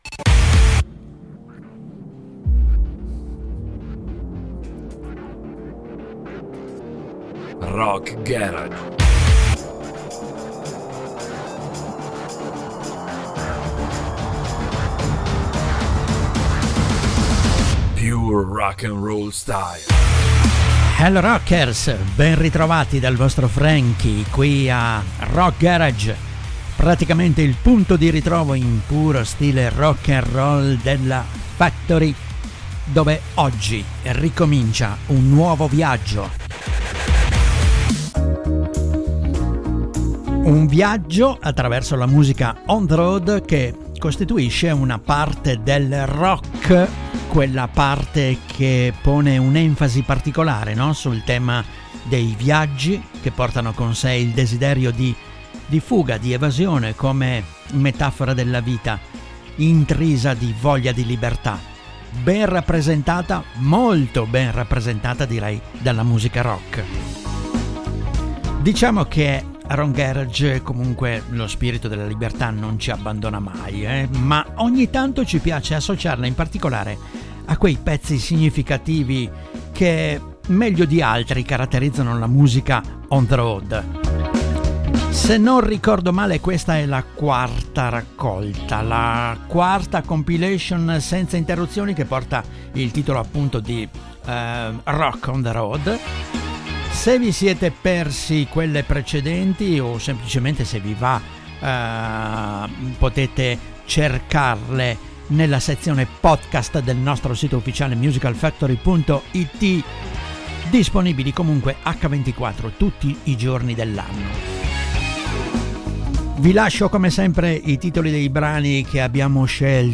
“Rock On The Road” quella parte del rock che pone un’enfasi particolare per i viaggi come desiderio di fuga, di evasione, come metafora della vita intrisa di voglia di libertà, con: